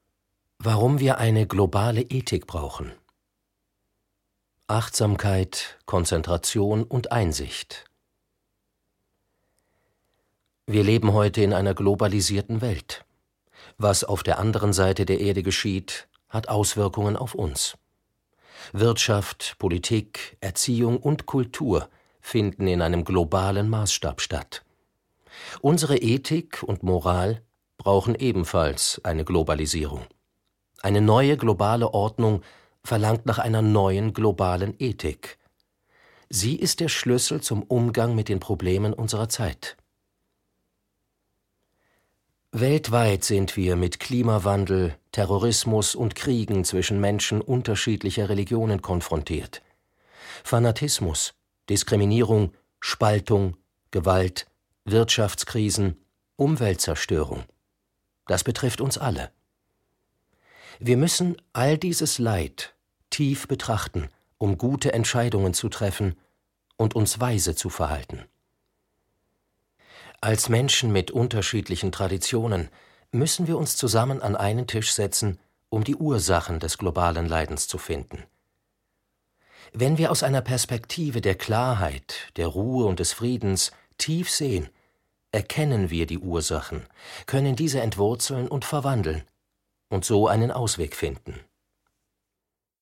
seine sanfte, wohlklingende Stimme geliehen.